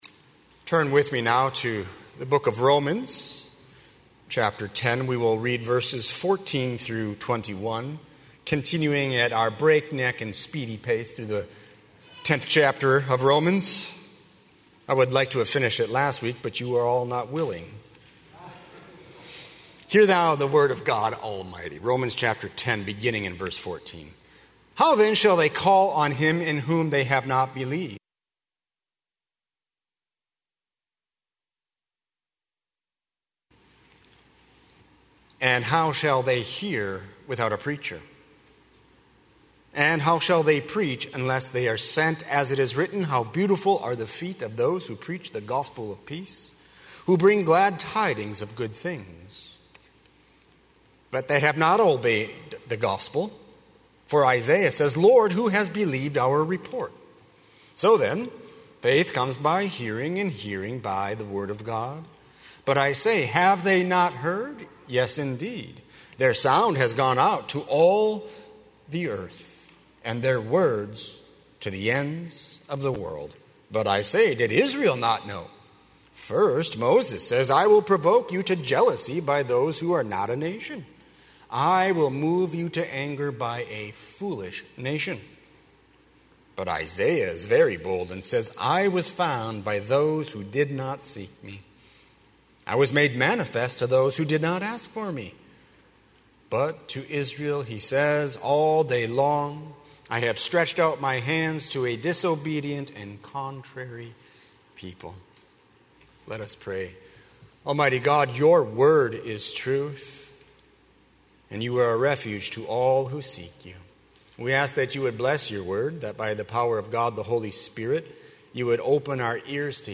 00:00 Download Copy link Sermon Text Romans 10:14–21